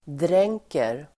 Uttal: [dr'eng:ker]